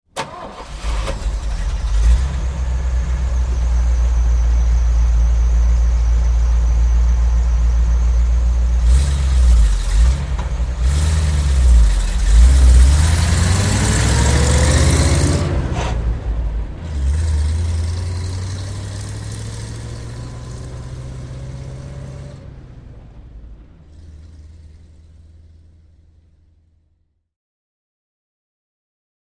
Звук завода и движения эвакуатора